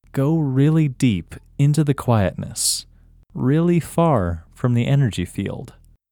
OUT – English Male 11